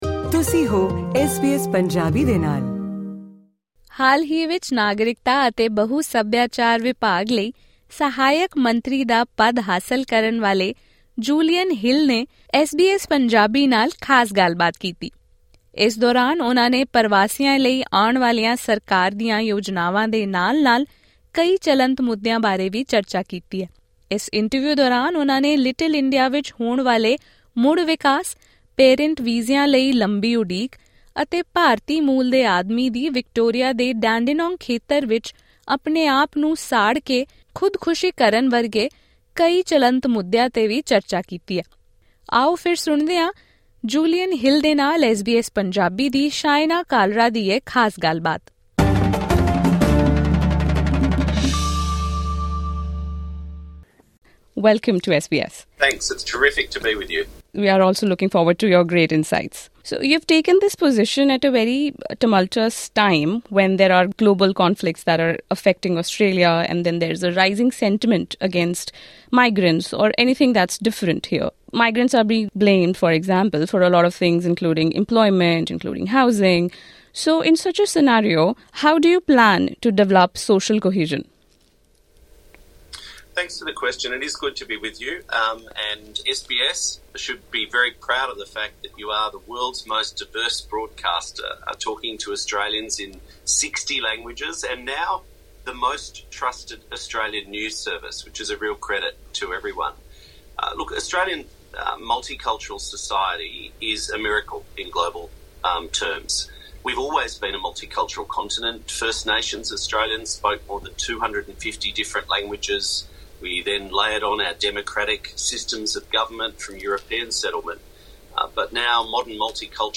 ਸਥਾਈ ਵੀਜ਼ੇ 'ਤੇ ਮਾਪਿਆਂ ਨੂੰ ਆਸਟ੍ਰੇਲੀਆ ਲਿਆਉਣ ਲਈ ਪ੍ਰਵਾਸੀ ਭਾਈਚਾਰਿਆਂ ਦੀ ਮਹੱਤਵਪੂਰਨ ਮੰਗ 'ਤੇ ਨਾਗਰਿਕਤਾ ਅਤੇ ਬਹੁ-ਸੱਭਿਆਚਾਰ ਦੇ ਸਹਾਇਕ ਮੰਤਰੀ ਜੂਲੀਅਨ ਹਿੱਲ ਨੇ ਕਿਹਾ ਹੈ ਕਿ ਆਸਟ੍ਰੇਲੀਆ ਗਿਣੇ ਚੁਣੇ ਬੁਜ਼ੁਰਗਾਂ ਨੂੰ ਹੀ ਇੱਥੇ ਲਿਆ ਸਕਦਾ ਹੈ। ਪੇਸ਼ ਹੈ ਮੰਤਰੀ ਜੂਲੀਅਨ ਹਿੱਲ ਨਾਲ ਐਸ ਬੀ ਐਸ ਪੰਜਾਬੀ ਦੀ ਵਿਸਥਾਰਿਤ ਇੰਟਰਵਿਊ।